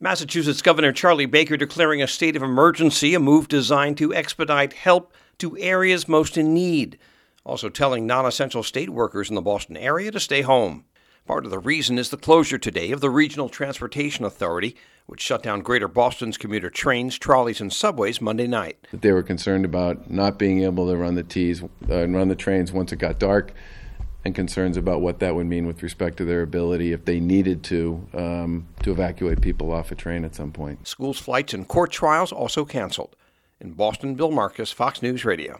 Massachusetts Governor Charlie Baker declaring a state of emergency Monday.